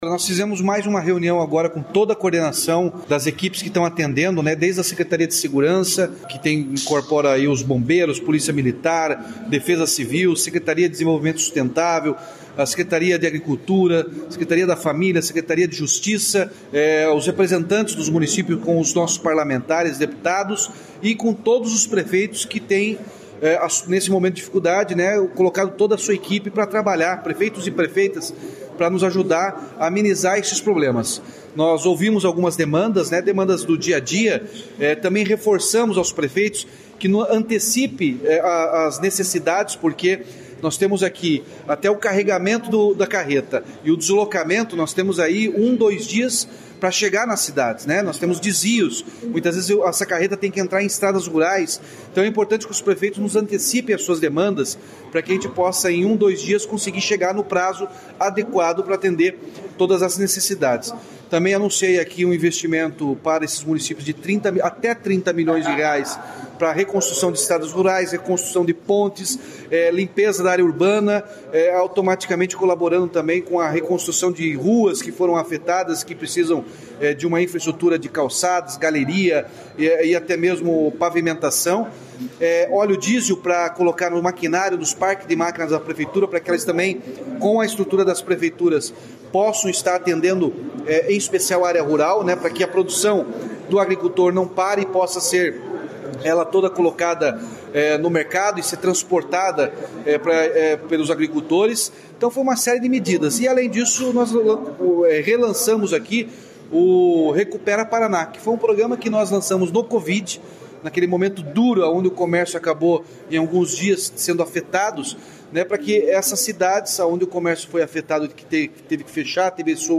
Sonora do governador Ratinho Junior sobre as medidas adotadas pelo Estado para auxílio aos municípios afetados pelas fortes chuvas no Paraná